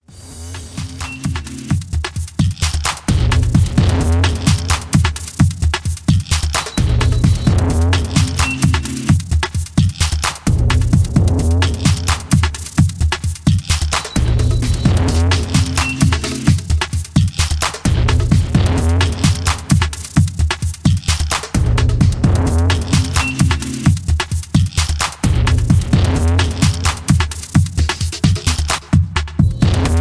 Positive dynamic techno house track